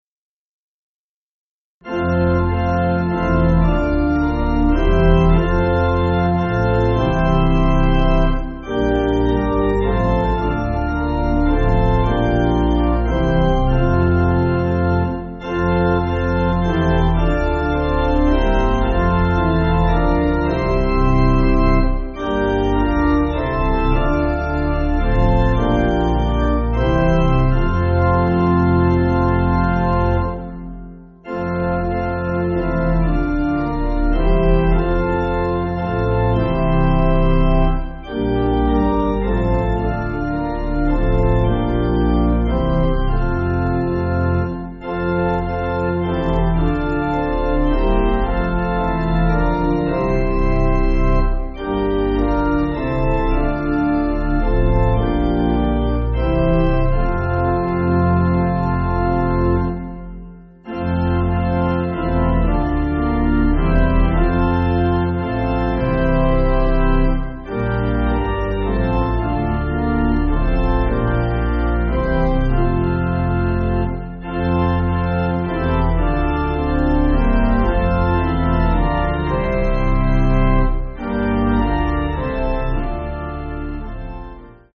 Medieval Hymn
Organ